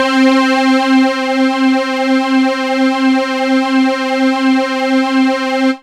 SYNTH GENERAL-2 0003.wav